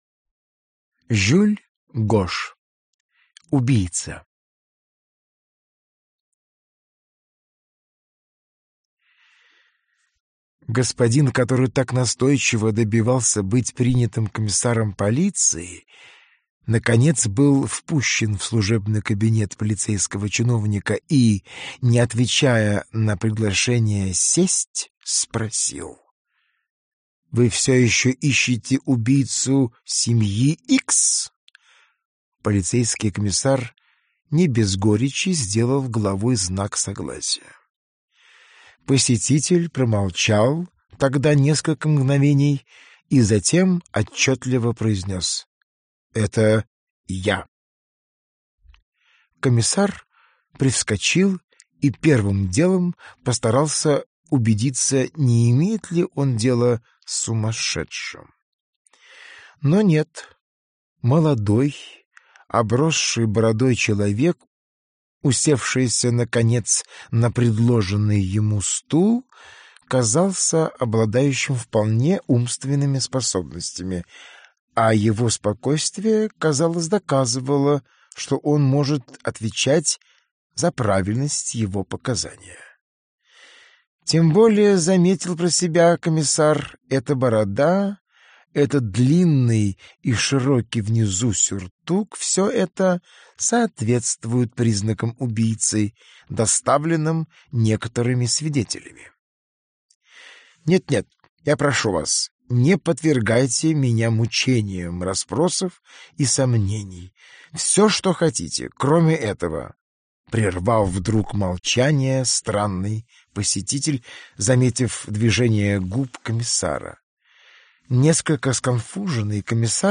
Аудиокнига Юмористические рассказы зарубежных писателей в исполнении Валерия Гаркалина | Библиотека аудиокниг